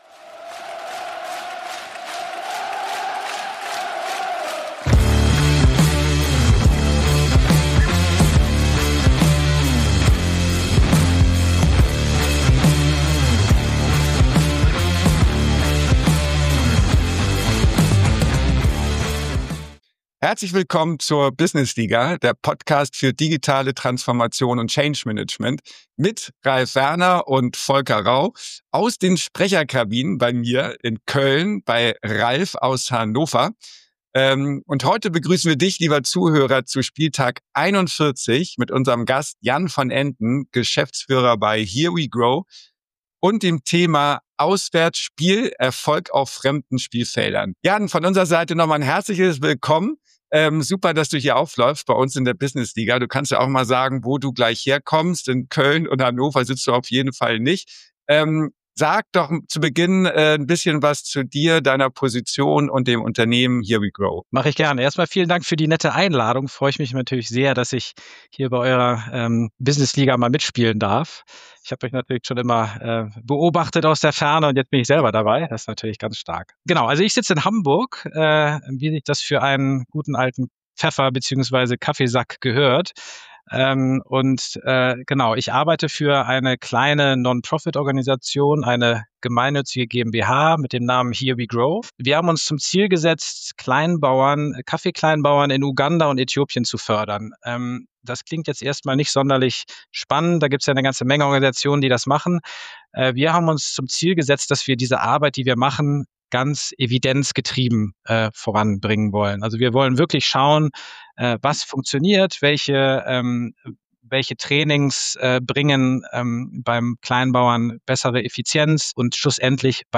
Unser Interview beleuchtet auch die Notwendigkeit, lokale Wertvorstellungen zu verstehen und wie HereWeGrow versucht, die Lebensbedingungen der Kaffeebauern zu verbessern.